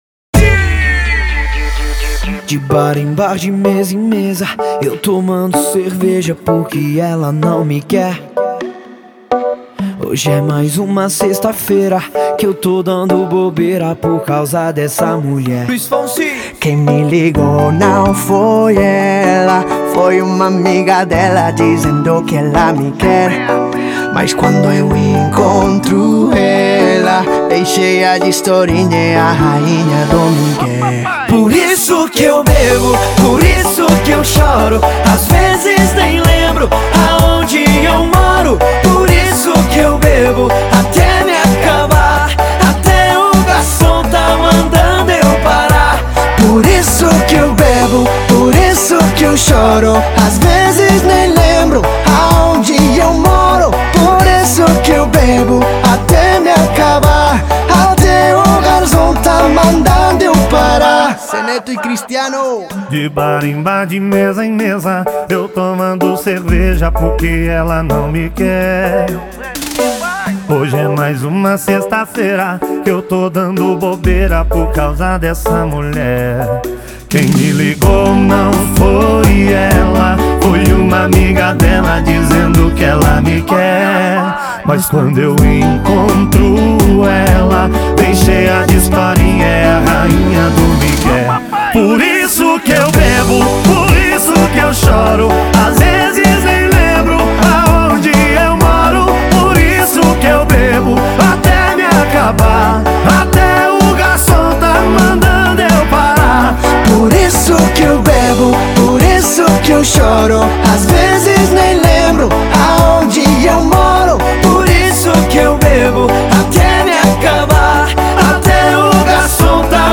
это зажигательная песня в жанре регетон и sertanejo